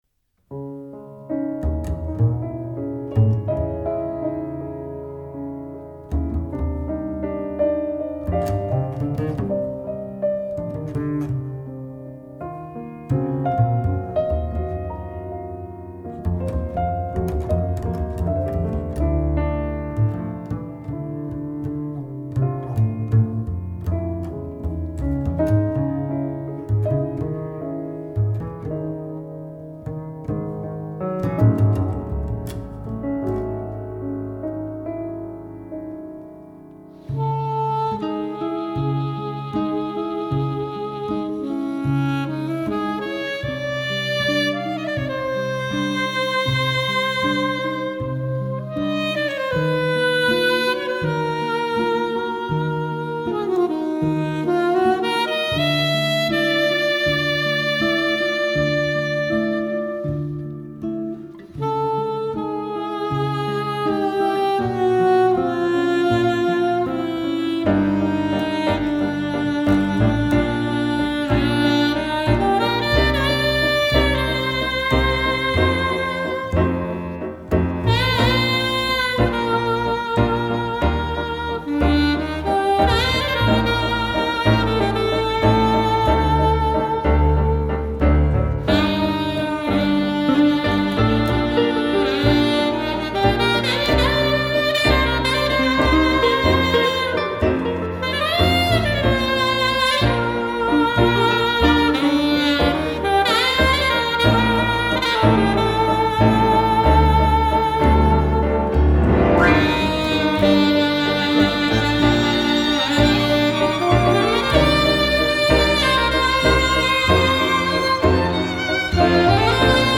sax
viol
bass
Hörbeispiele Quintett